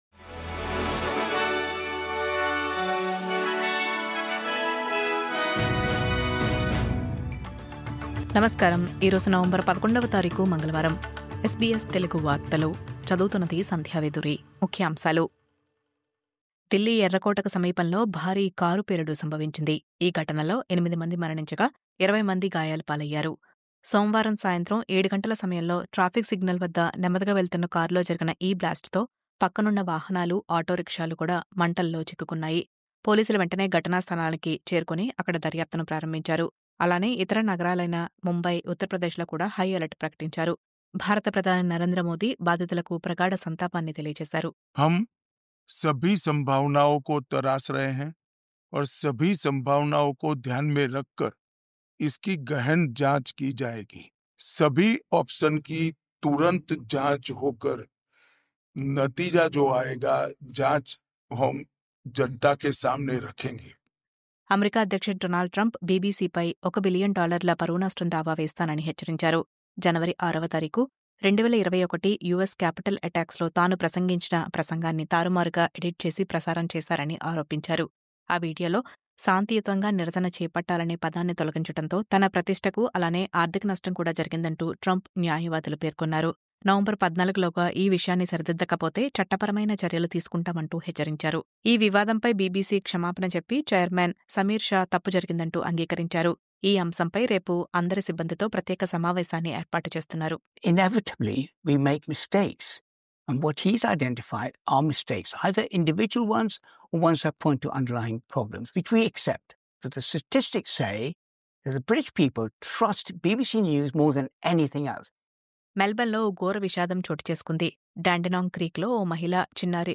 News update: డొనాల్డ్ ట్రంప్‌ బీబీసీపై $1 బిలియన్ దావా ...ఆస్ట్రేలియా అంతటా రిమెంబ్రెన్స్ డే కార్యక్రమాలు ..